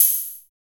NOISE OHH.wav